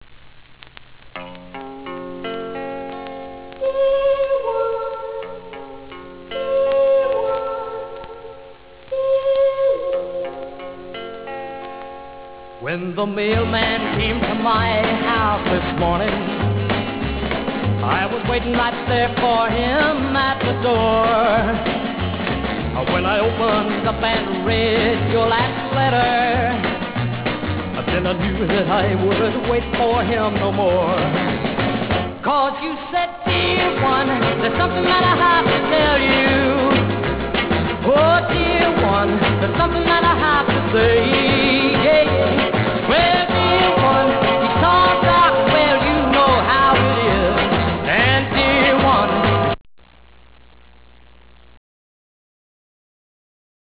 Garage Rock WAV Files